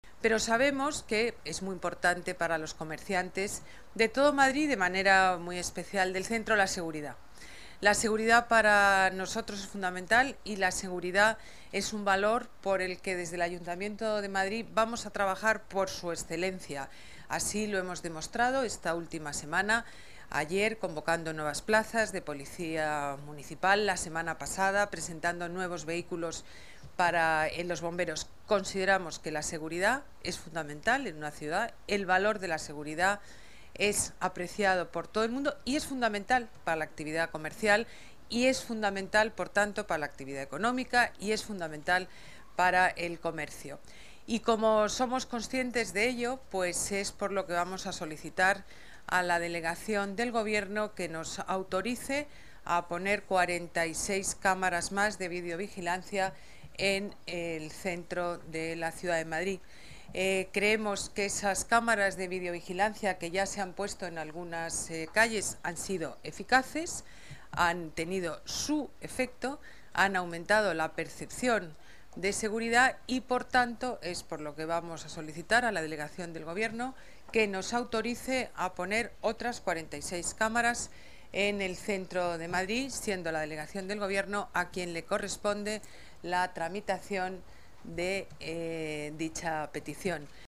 Nueva ventana:Declaraciones de la alcaldesa